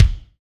SMOOTH K.wav